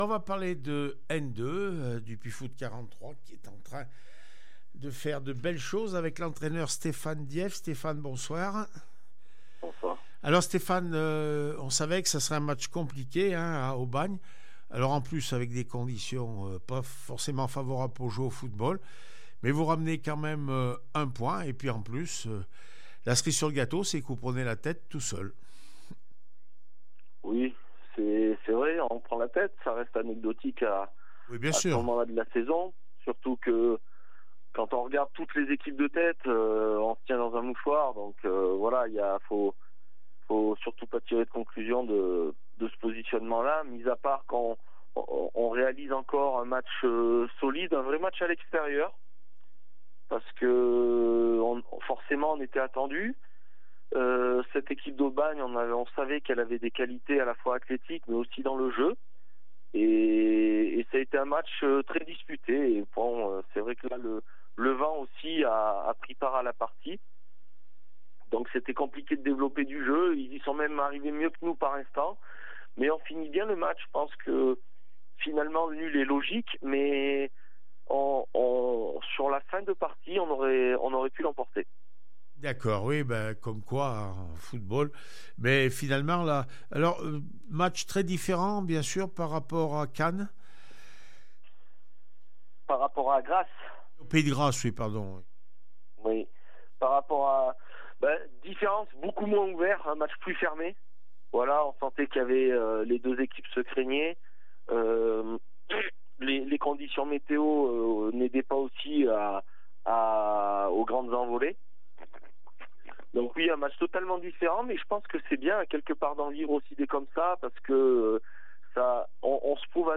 4 décembre 2023   1 - Sport, 1 - Vos interviews